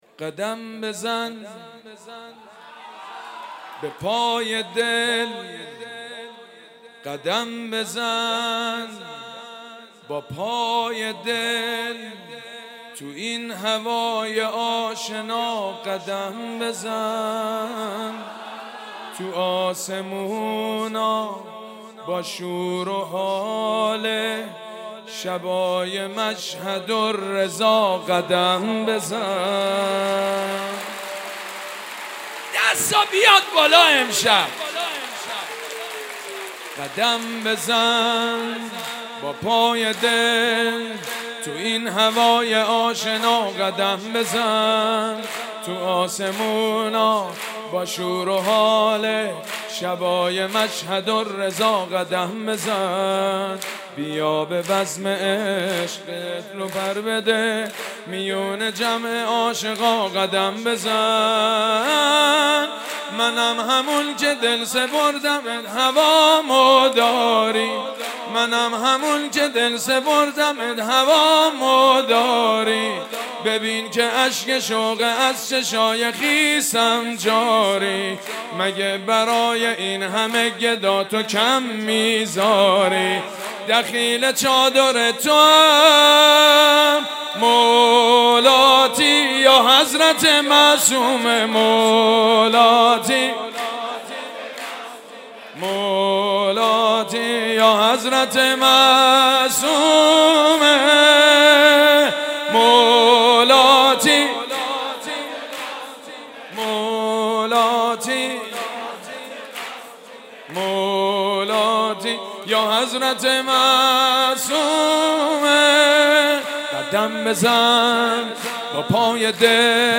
سرود: قدم بزن با پای دل